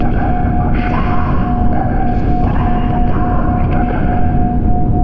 divination-magic-sign-circle-loop.ogg